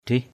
/d̪rih/ (t.) muồi = blet, trop mûr. baoh kayau tathak drih _b<H ky~@ tEK d{H trái cây chín muồi.